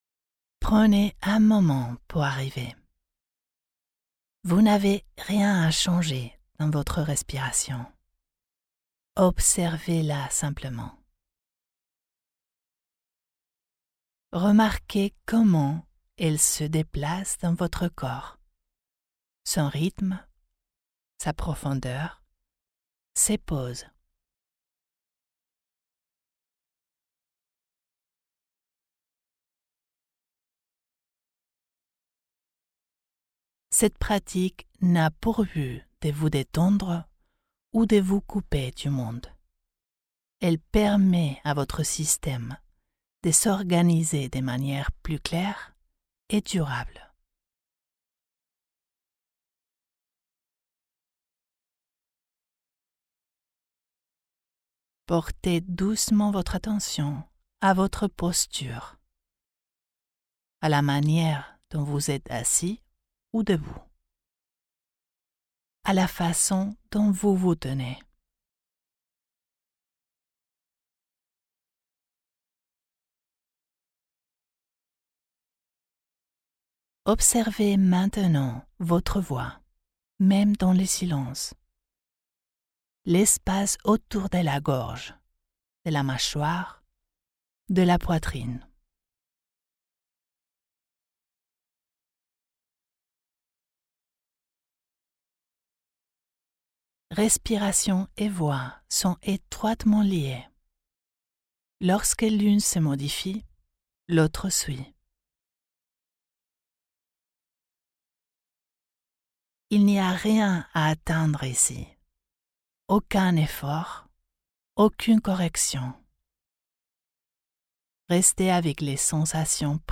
Respirez-avec-moi-AOP-FBR.mp3